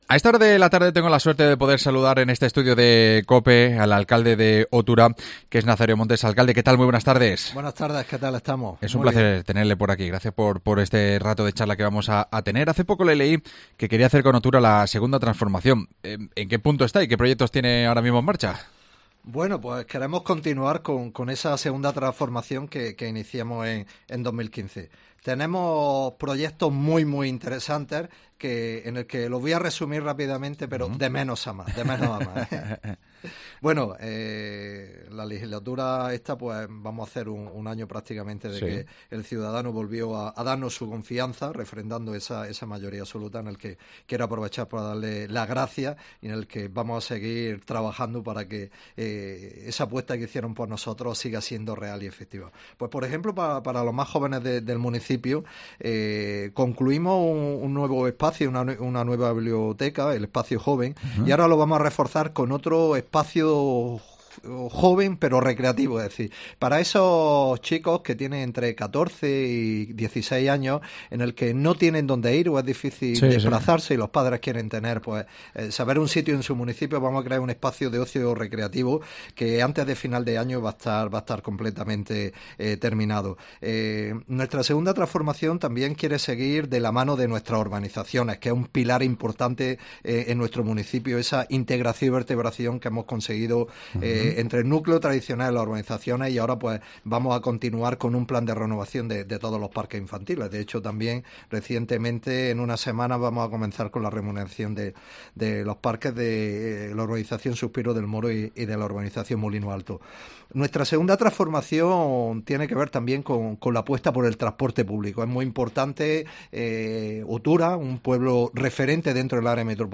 AUDIO: Su alcalde, Nazario Montes, ha estado en COPE repasando los grandes proyectos del municipio que pasan por la piscina climatizada o la...